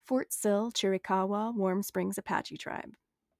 Fort Sill-Chiricahua-Warm Springs-Apache Tribe Pronunciation · Online Educator Resource · First Americans Museum